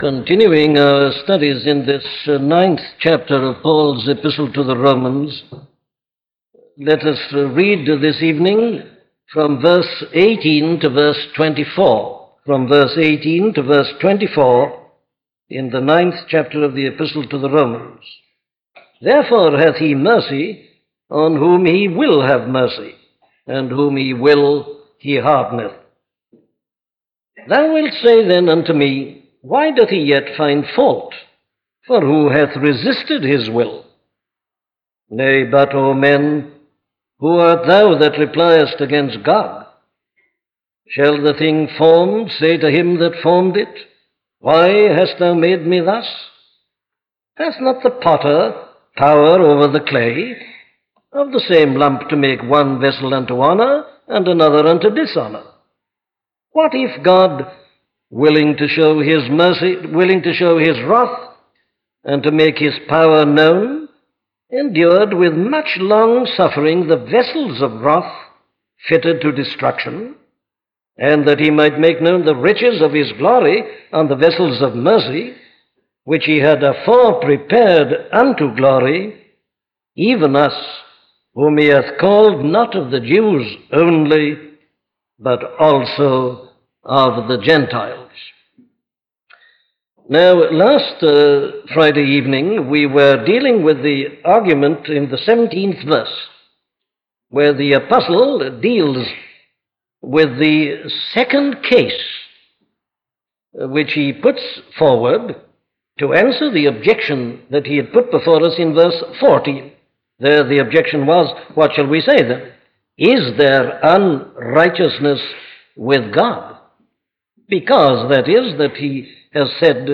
A collection of sermons on Sovereignty of God by Dr. Martyn Lloyd-Jones